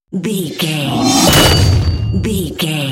Dramatic horror metal hit
Sound Effects
Atonal
heavy
intense
dark
aggressive